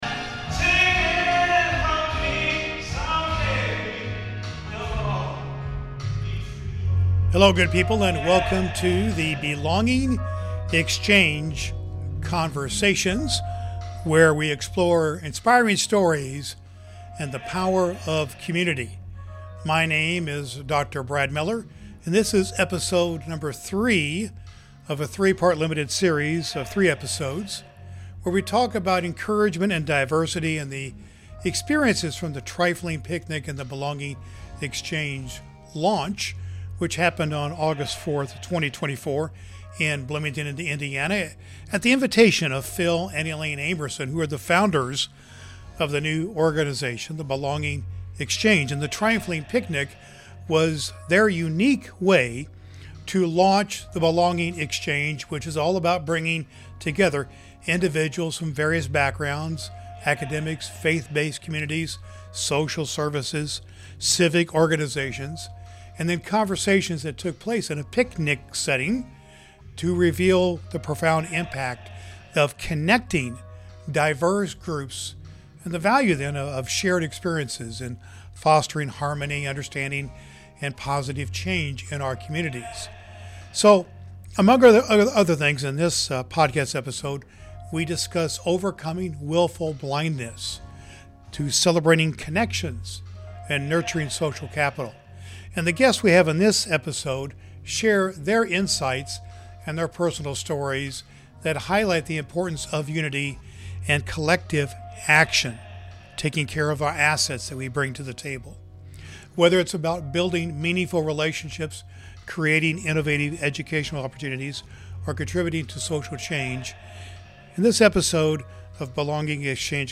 In this enriching episode of "To Be Encouraged," we dive into the power of community building, shared experiences, and fostering connections across diverse backgrounds. Recorded at the Trifling Picnic, an event designed to bring individuals together, our guests highlight the underlying importance of harmony, belonging, and mutual support.